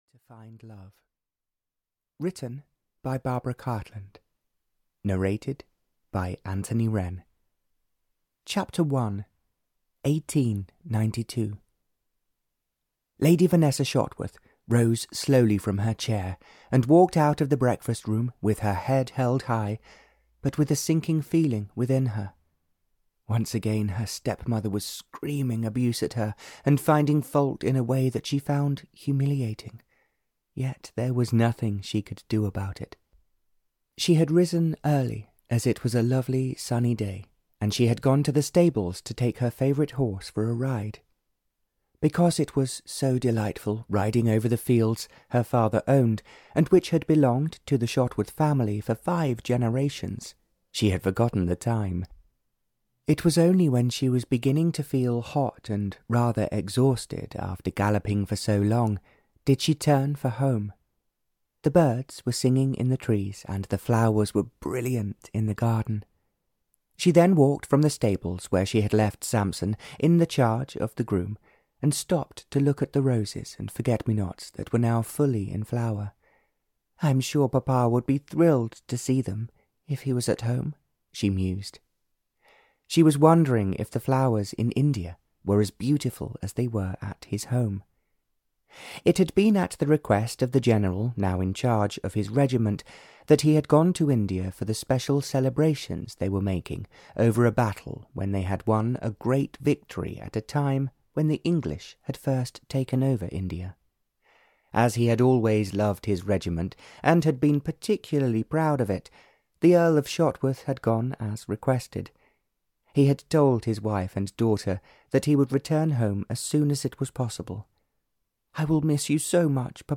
A Strange Way to Find Love (EN) audiokniha
Ukázka z knihy